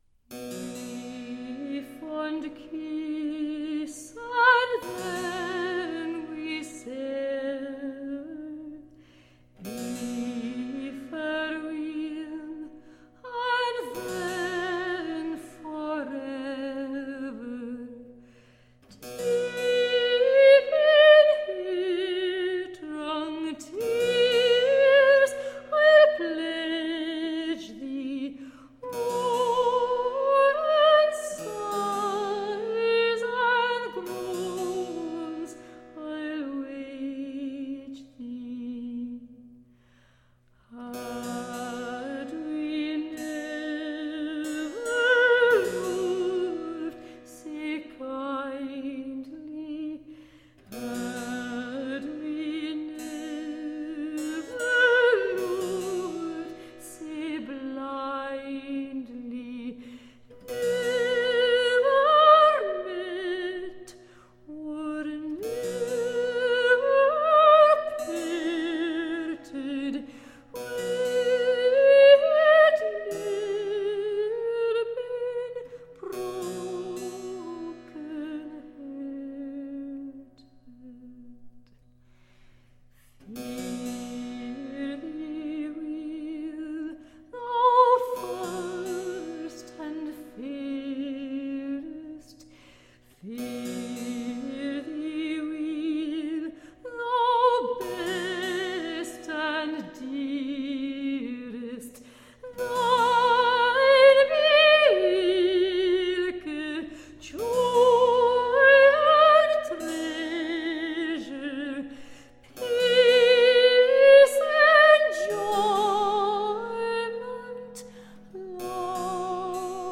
Tagged as: Classical, Folk, Choral, Celtic